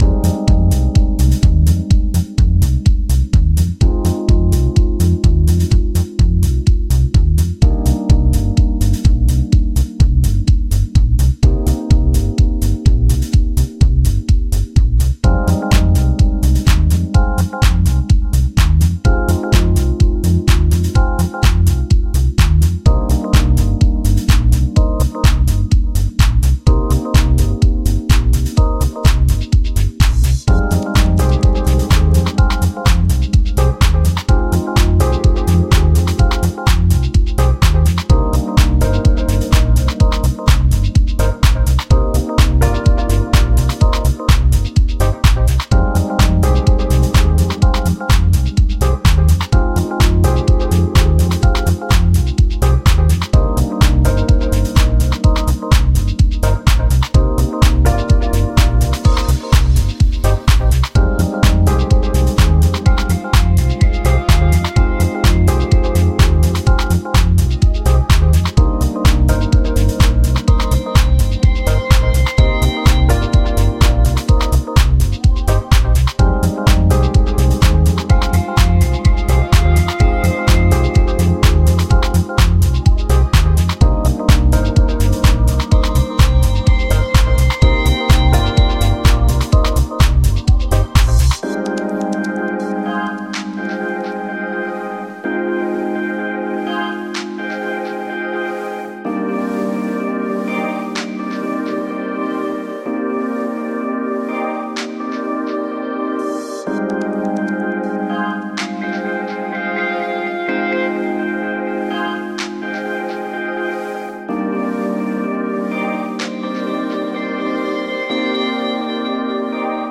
Звук для стильных без слов